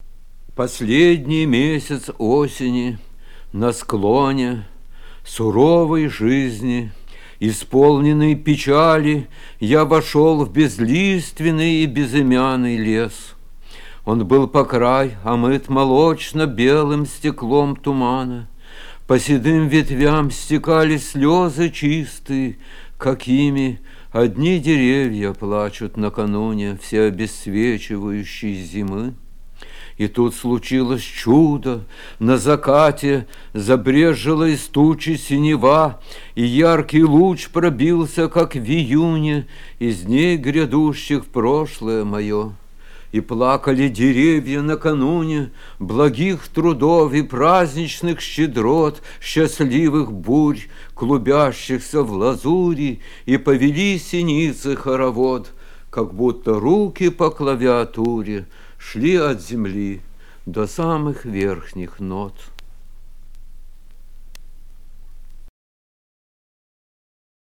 arsenij-tarkovskij-v-poslednij-mesyats-oseni-na-sklone-chitaet-avtor